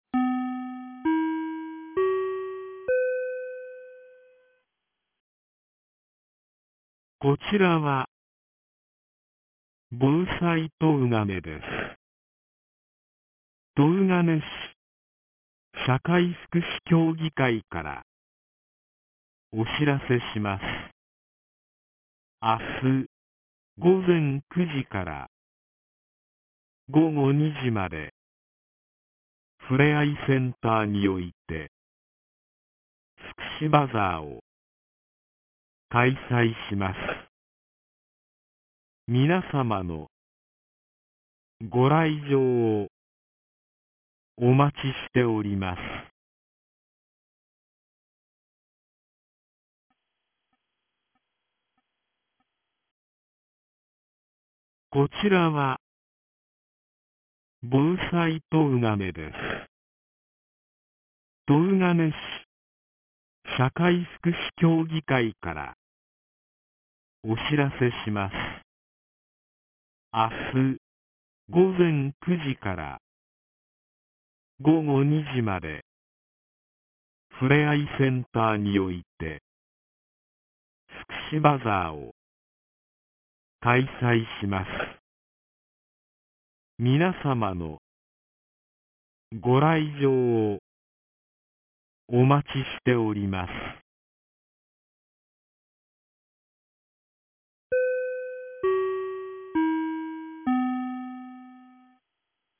2025年07月05日 16時02分に、東金市より防災行政無線の放送を行いました。